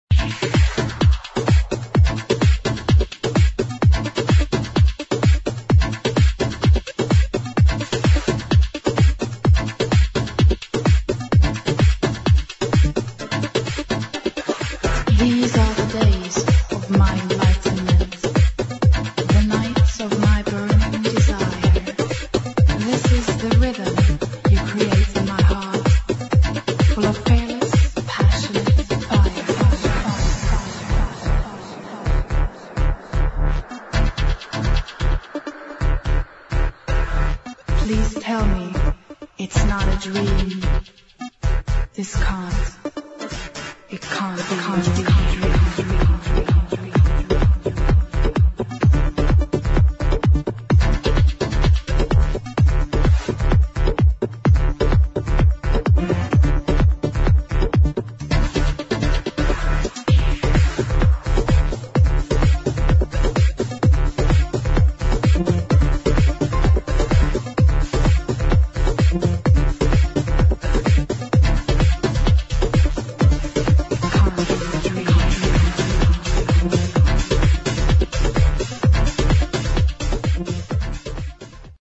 [ HOUSE / ELECTRO ]
フレンチ女性ヴォーカル・エレクトロ・ハウス・チューン！